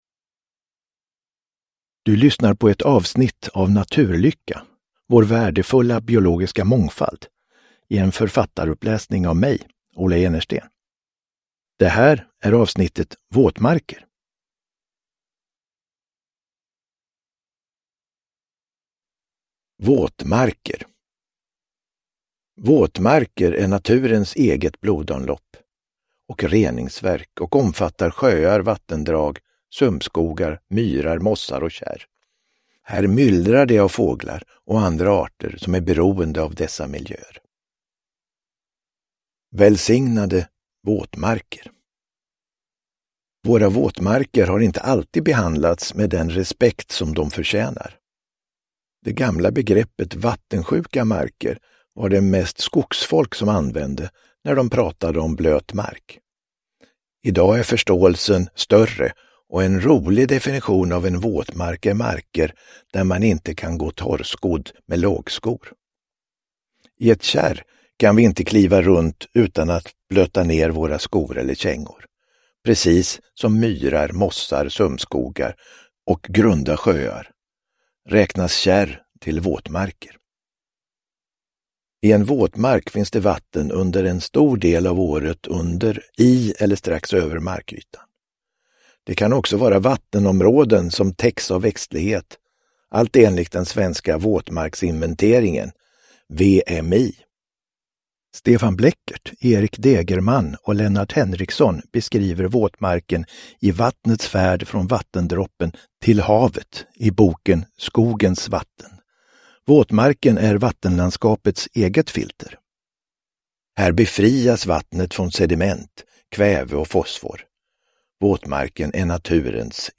Naturlycka - Våtmarker – Ljudbok – Laddas ner